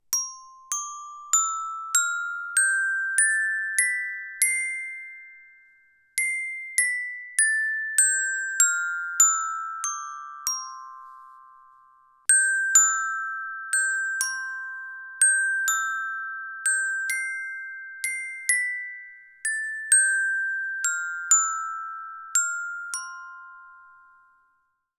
NINO Percussion C Major Scale Glockenspiel (NINO901)
Tonumfang c, d, e, f, g, a, b, c. Das Kullerauge und die blauen Flossen runden das Instrument harmonisch, zur Freude aller Kinder ab.